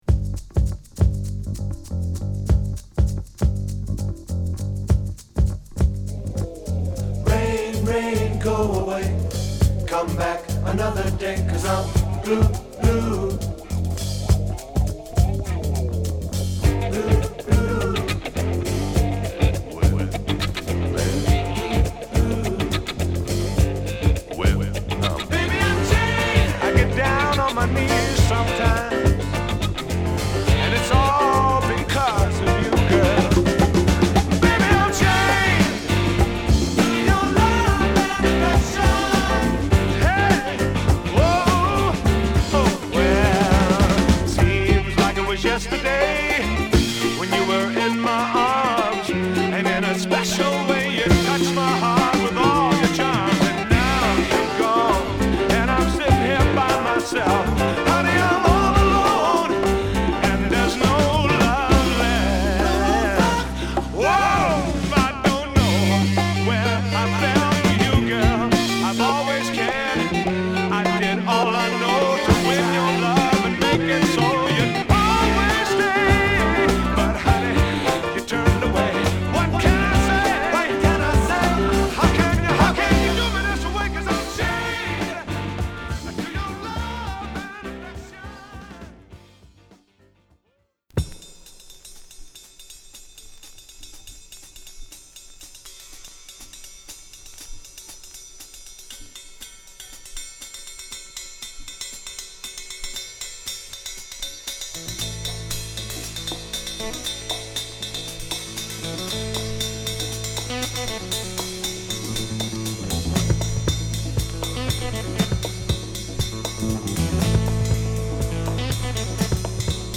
ヘヴィーなベースのイントロからカッコ良いファンキーロックA
熱量控えめ、クールなオルガンに後半の展開も◎なジャズロックB